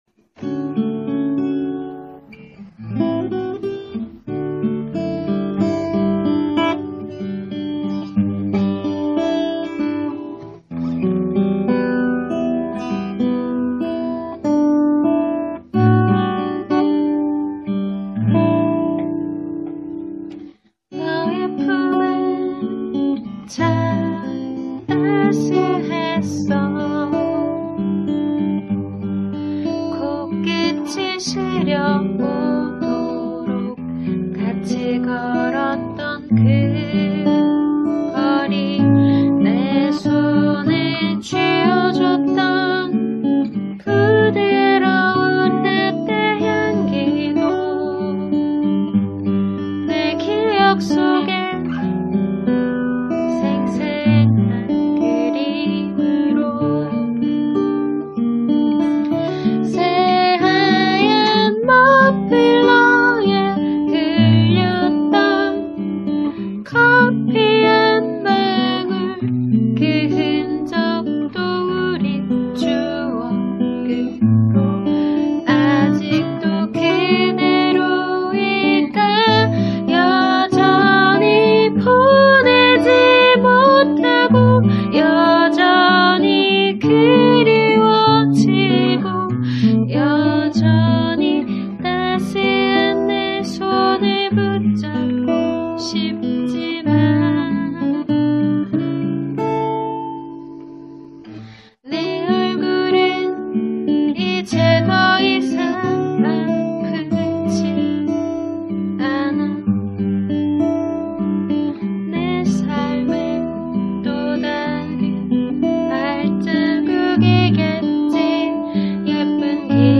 클라이막스가 뚜렷하던 내 노래와 달리 서정적이고 감미로운 연주는 쌀쌀한 바람이 불어오던 그 가을밤과 참 잘어울렸다.